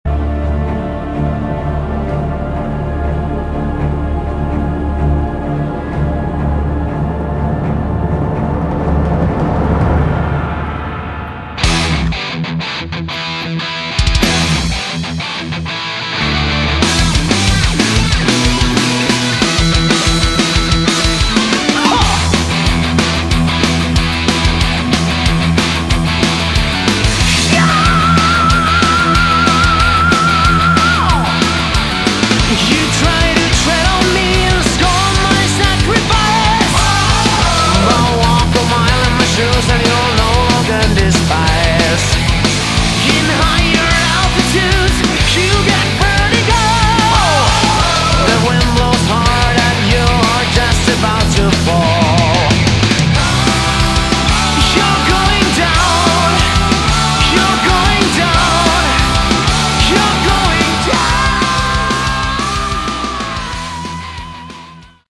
Category: Melodic Metal
lead vocals
guitars, backing vocals
bass, backing vocals
drums